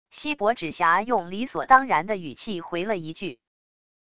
锡箔纸侠用理所当然的语气回了一句wav音频